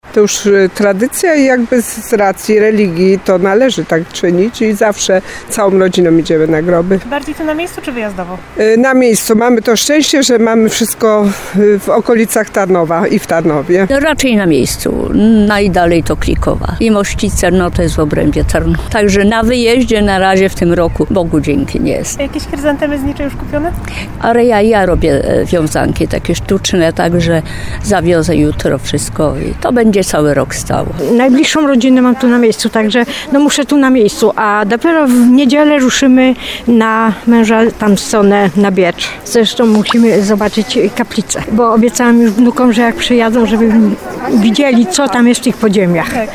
Większość tarnowian, z którymi rozmawialiśmy zamierza spędzić uroczystość Wszystkich Świętych na lokalnych cmentarzach.
29sonda-cmentarze.mp3